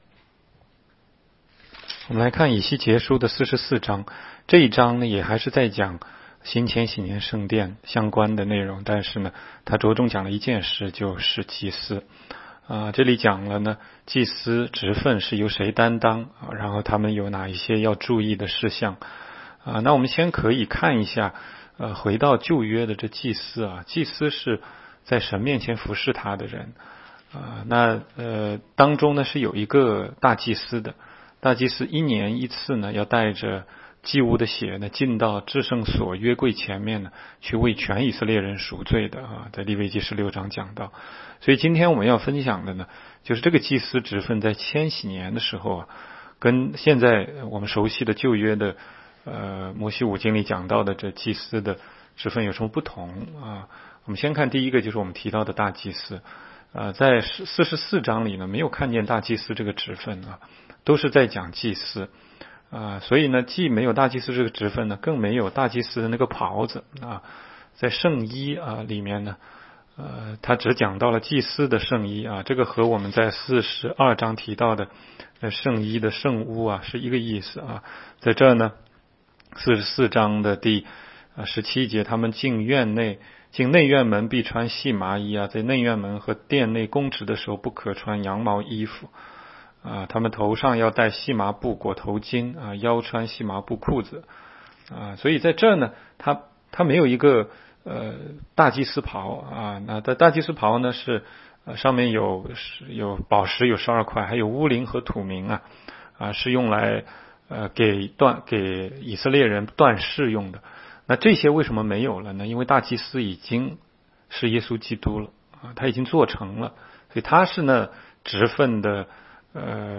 16街讲道录音 - 每日读经 -《以西结书》44章